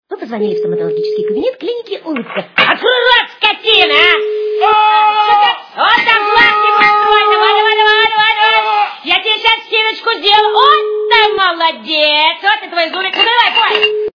» Звуки » другие » Автоответчик - кабинет стоматолога - Вы позвонили в стоматологический кабинет клиники Улыбка. Открой рот скотина! Оставляйте свою заявочку и мы сделаем ваш рот лучезарным!
При прослушивании Автоответчик - кабинет стоматолога - Вы позвонили в стоматологический кабинет клиники Улыбка. Открой рот скотина! Оставляйте свою заявочку и мы сделаем ваш рот лучезарным! качество понижено и присутствуют гудки.